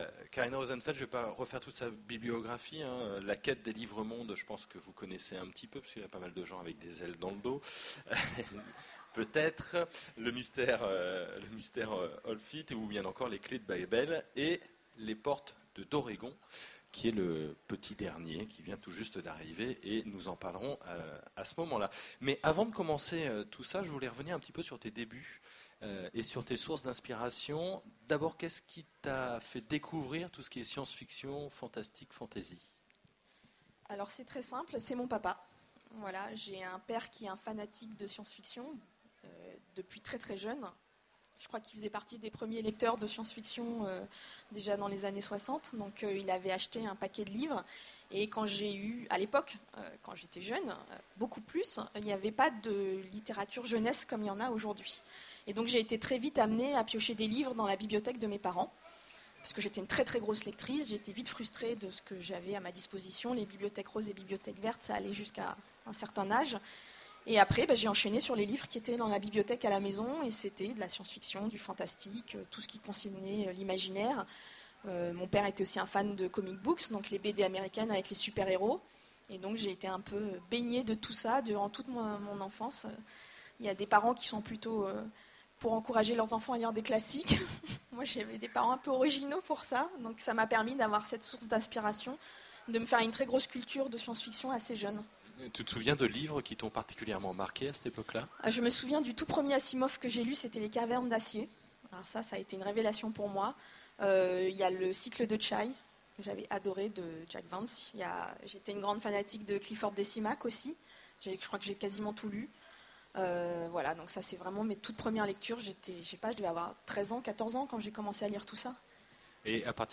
Utopiales 2010 : Rencontre